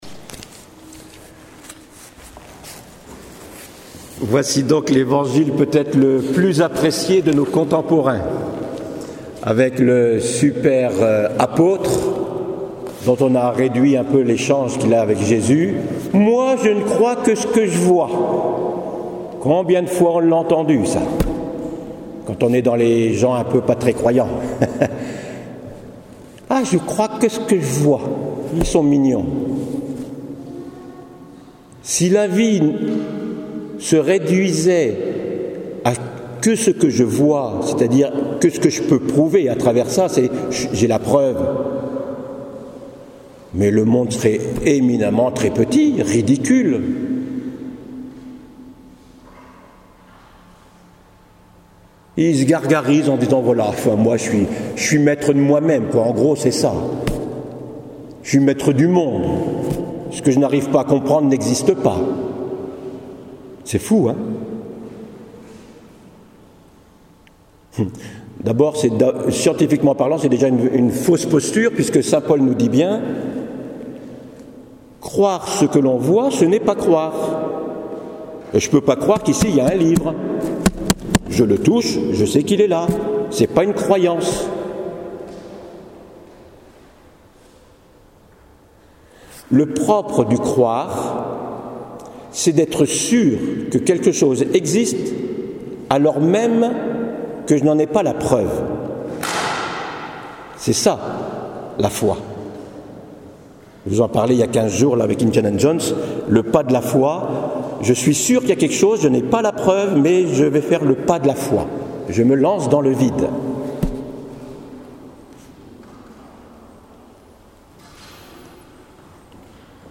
Voici l’homélie de ce jour en audio=> je ne crois que ce que je vois